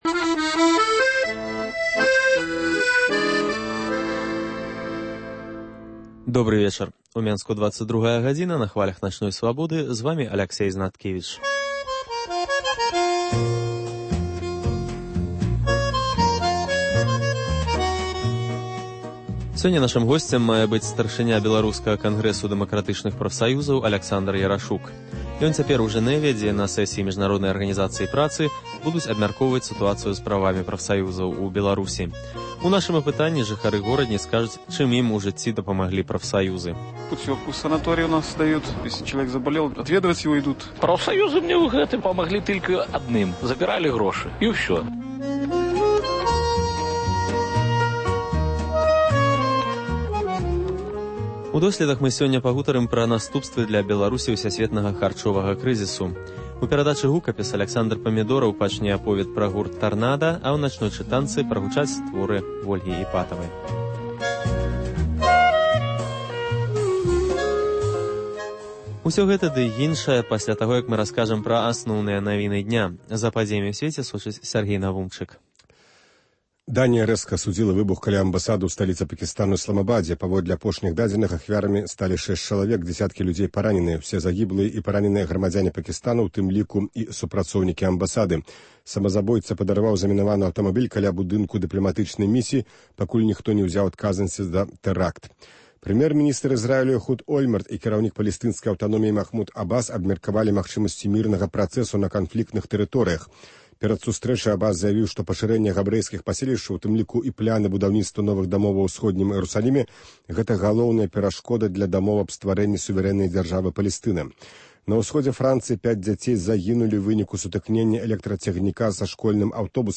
Вечаровы госьць, сацыяльныя досьледы, галасы людзей.